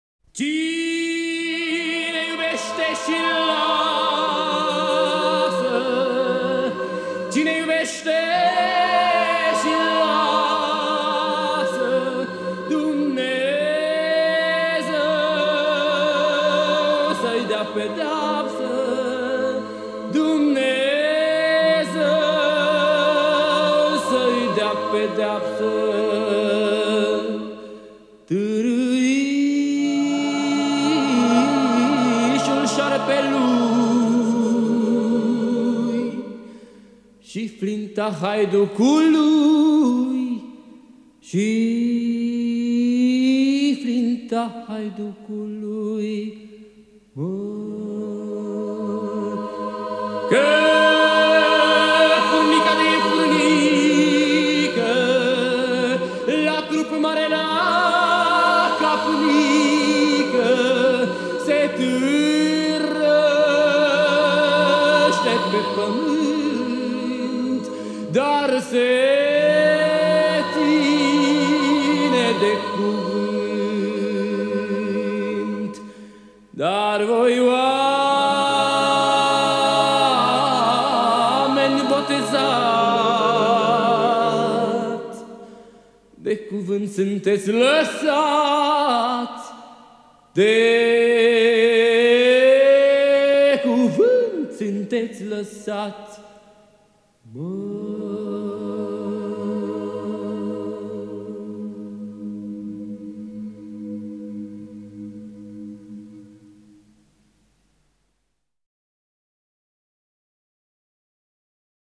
solist si dirijor